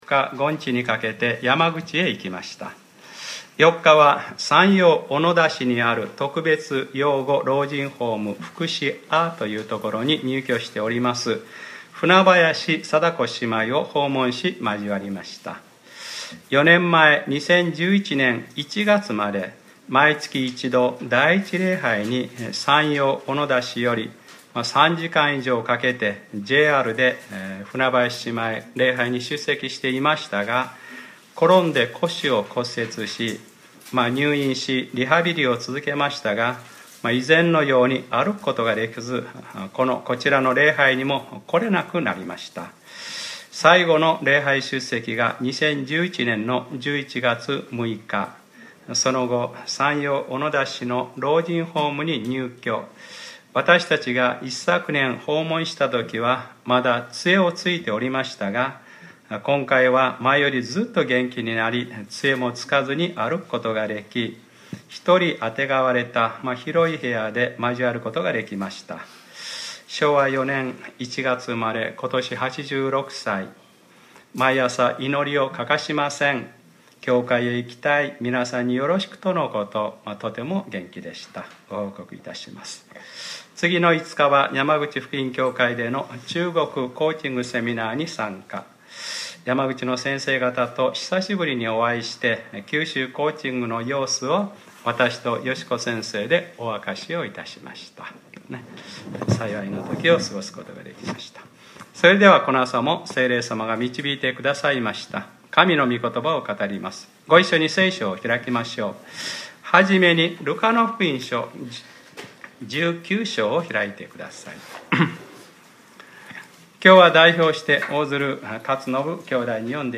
2015年08月09日（日）礼拝説教 『ルカｰ６６：きょう、救いがこの家に来ました。』